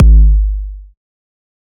EDM Kick 28.wav